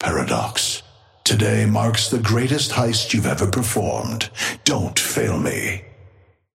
Amber Hand voice line - Paradox.
Patron_male_ally_chrono_start_02.mp3